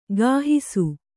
♪ gāhisu